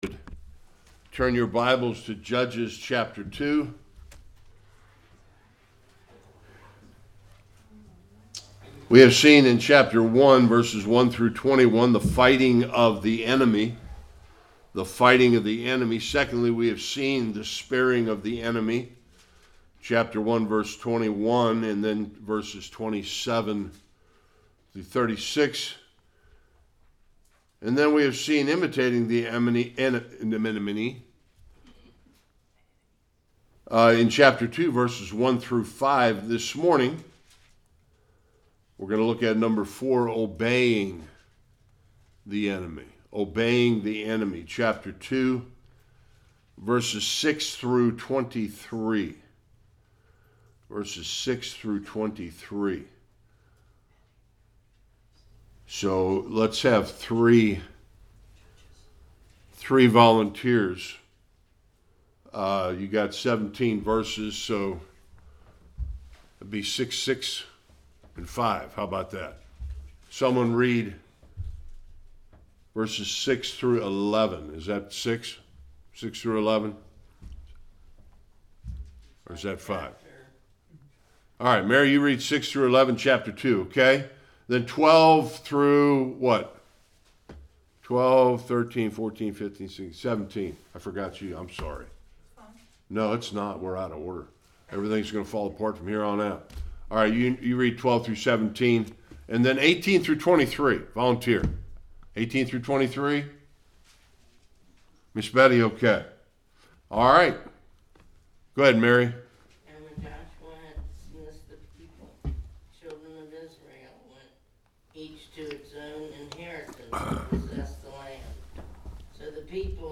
6-15 Service Type: Sunday School We will either conquer sin in our lives or it will conquer us. Topics: The sins of the new generation in Israel.